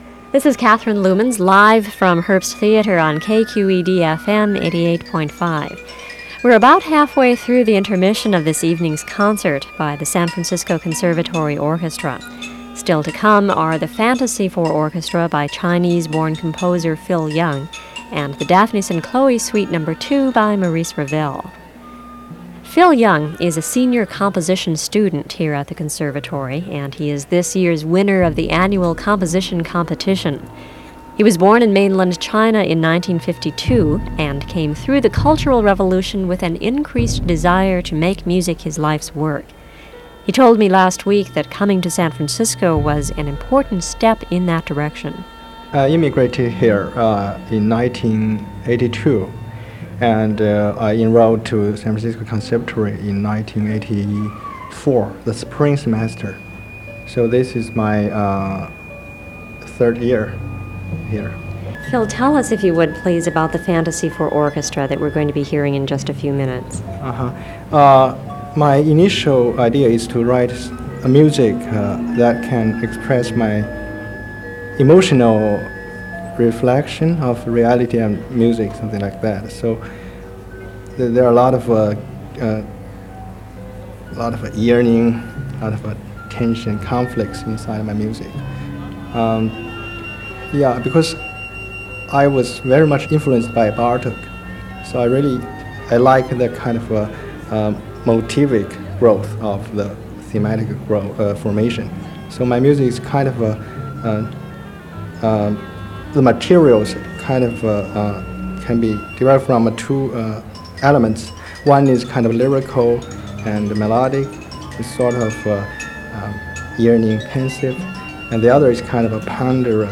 kqed-interview.m4a